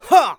xys发力8.wav 0:00.00 0:00.39 xys发力8.wav WAV · 33 KB · 單聲道 (1ch) 下载文件 本站所有音效均采用 CC0 授权 ，可免费用于商业与个人项目，无需署名。
人声采集素材